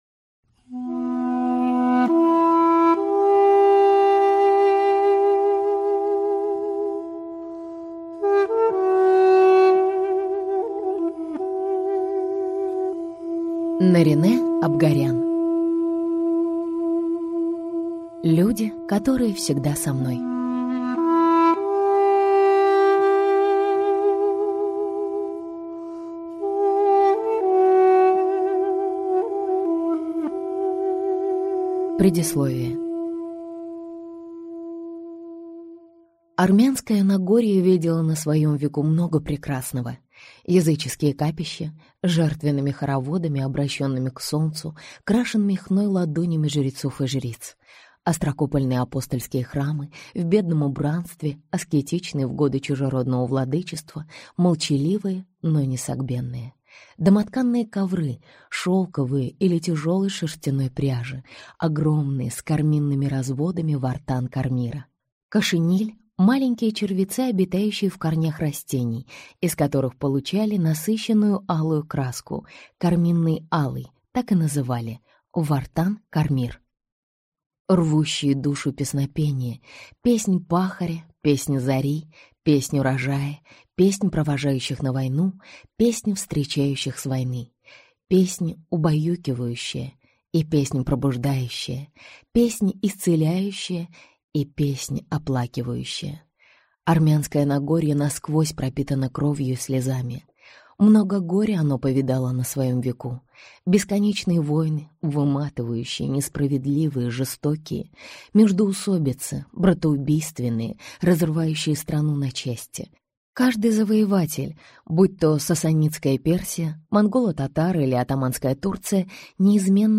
Аудиокнига Люди, которые всегда со мной | Библиотека аудиокниг